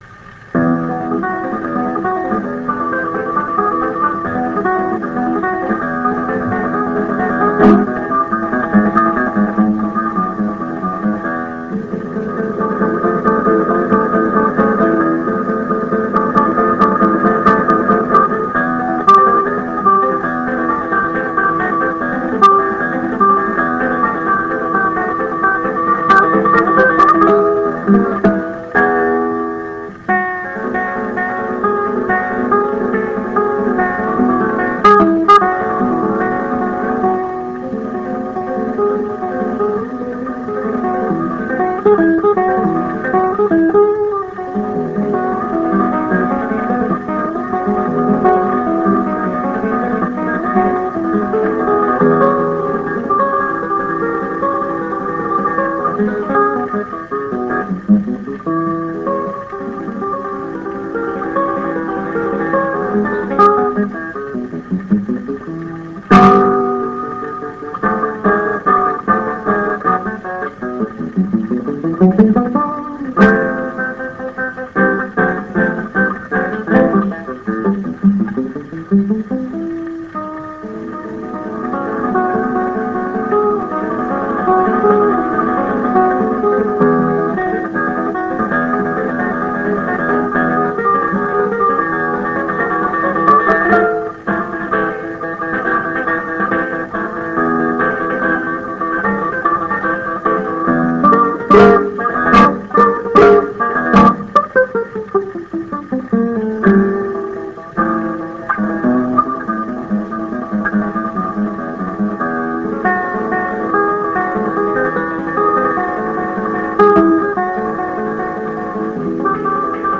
蓄音機から流れる音を録音してみました!!
ノスタルジックな雑音混じりの音楽を
ギター*ソロ
12インチSP盤